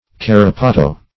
carapato - definition of carapato - synonyms, pronunciation, spelling from Free Dictionary